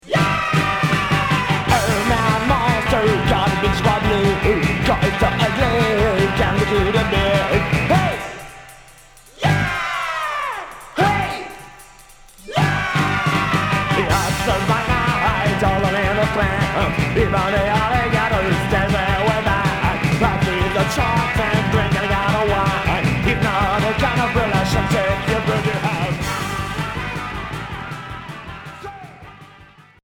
Psychobilly